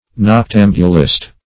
Noctambulist \Noc*tam"bu*list\, n.